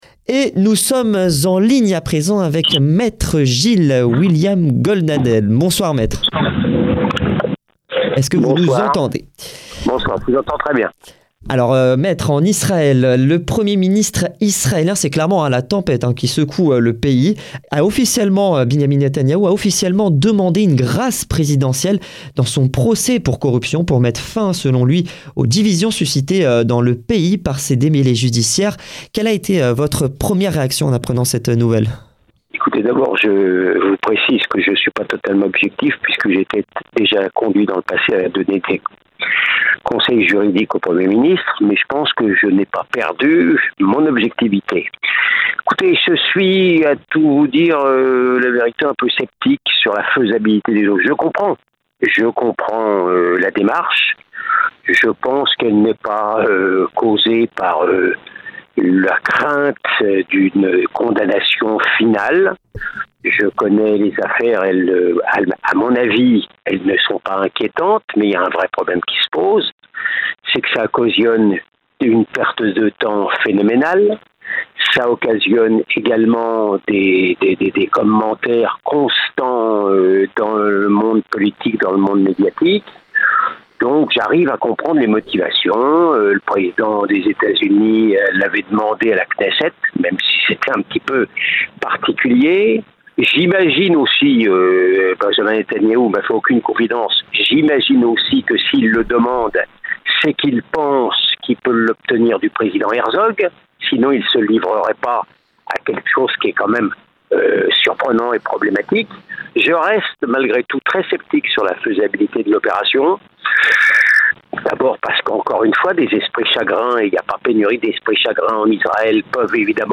Netanyahou a présenté une demande de grâce au président de l'Etat d'Israël. Interview de Gilles-William Goldnadel ancien avocat de chef du gouvernement israélien